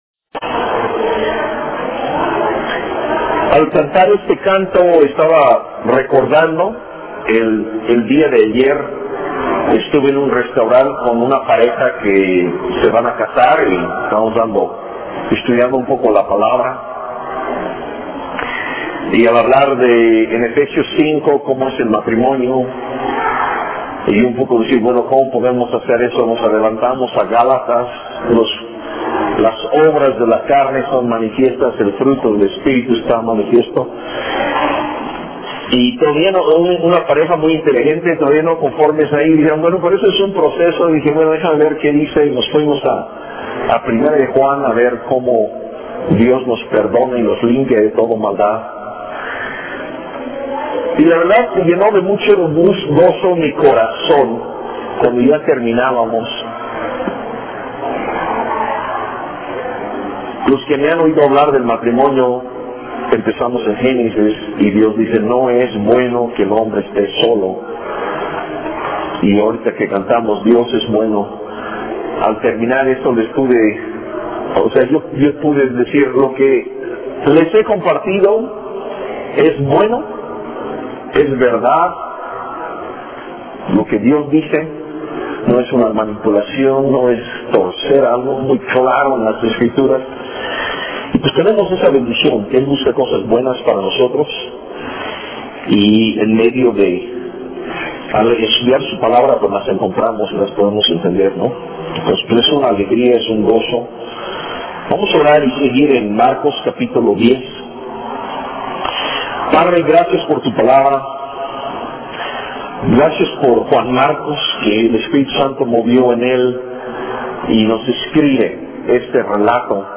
Sermones por Fechas